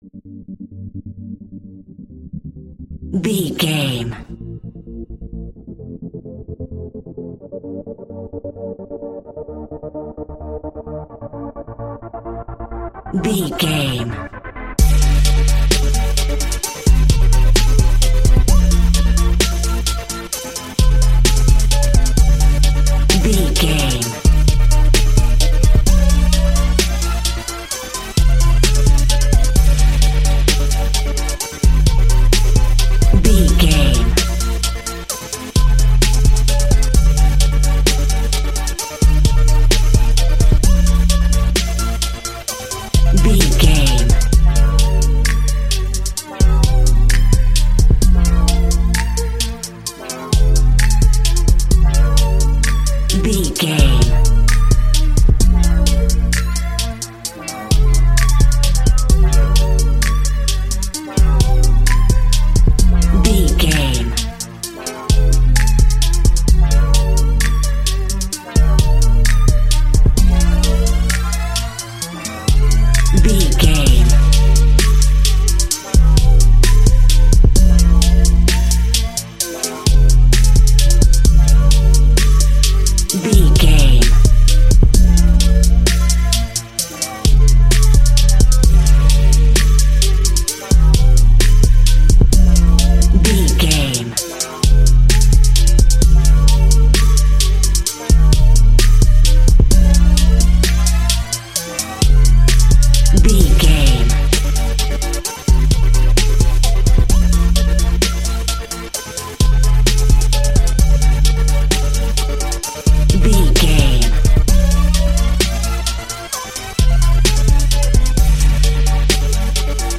Aeolian/Minor
chilled
laid back
groove
hip hop drums
hip hop synths
piano
hip hop pads